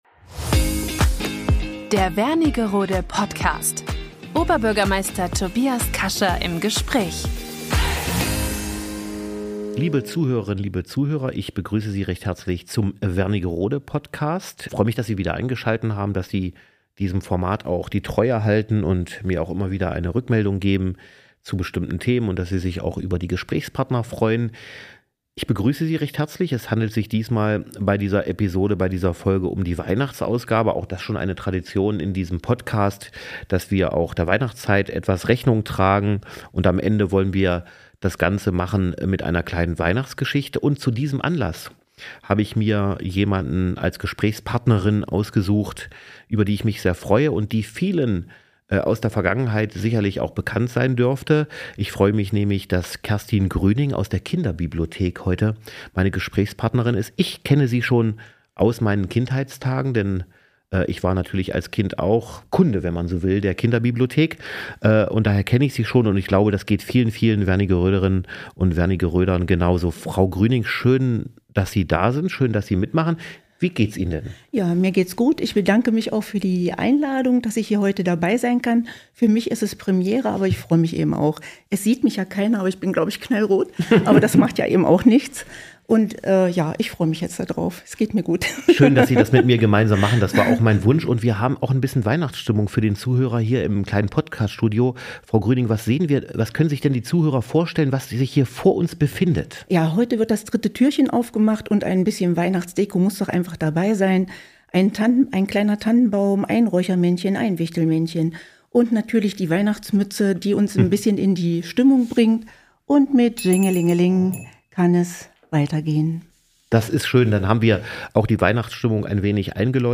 Sie liest eine stimmungsvolle Weihnachtsgeschichte und erzählt, wie die Adventszeit in der Kinderbibliothek erlebt wird. Eine Folge zum Zuhören, Innehalten und Einstimmen auf Weihnachten – für kleine und große Hörerinnen und Hörer.